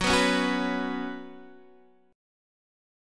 F#sus4 chord